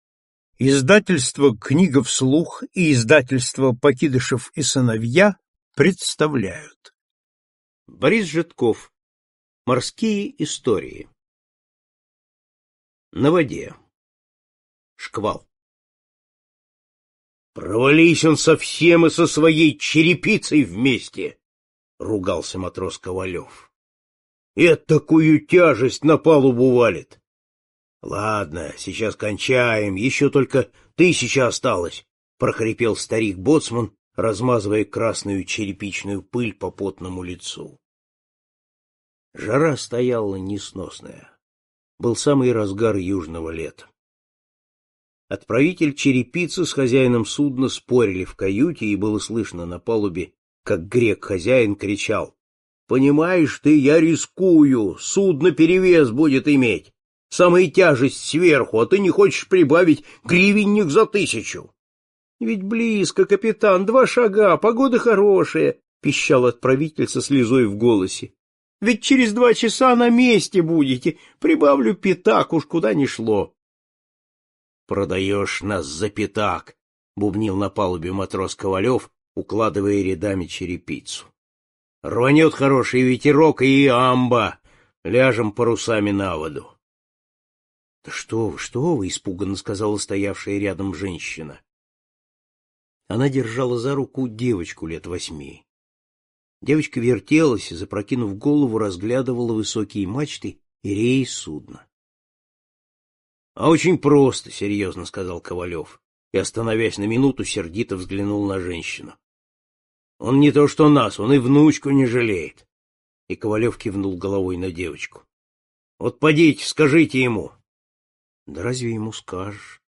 Морские истории - аудио повесть Житкова - слушать онлайн